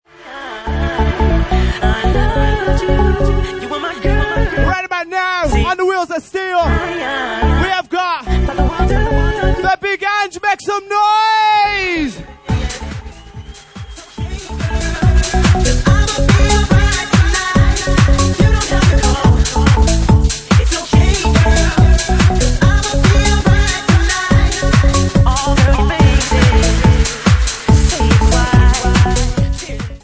Genre:Bassline House
Bassline House at 71 bpm